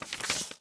bookturn3.ogg